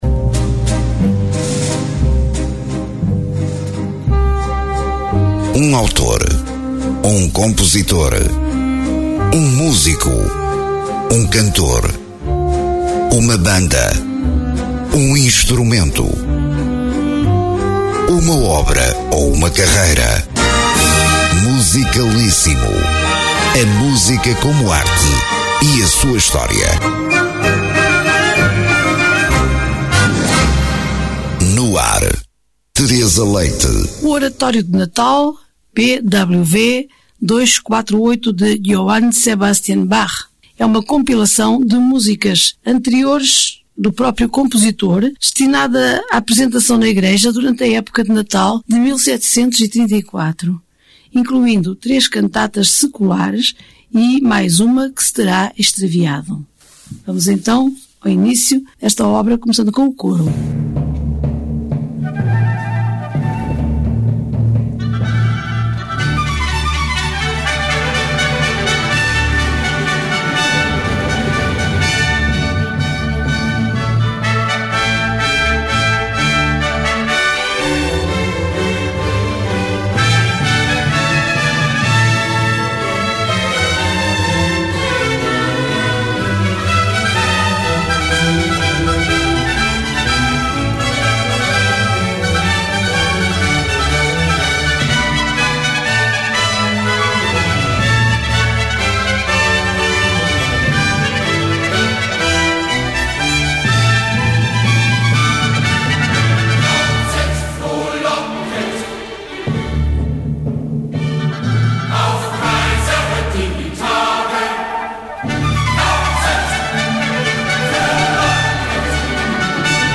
Ouvimos até agora a parte inicial da primeira oratória, continuando com a segunda parte, Playlist: Johann Sebastian Bach – BWV 248; 1.Parte I, Coro – Jauchzet, frohlocket!
Recitativo
(Tenor)
(Contralto)
(Baixo)